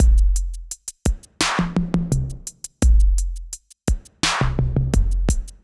Index of /musicradar/80s-heat-samples/85bpm